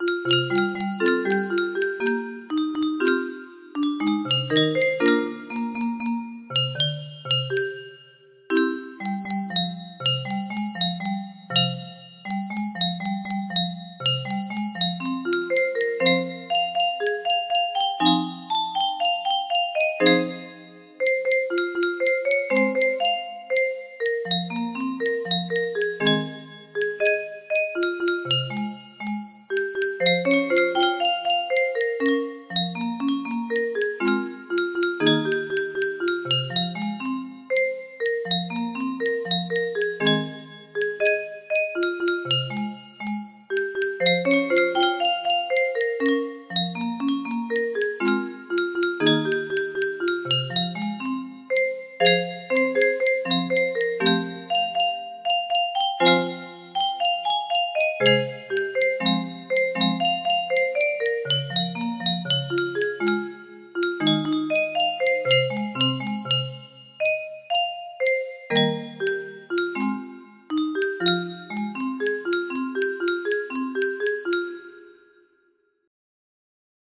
Full range 60
for Music box.mid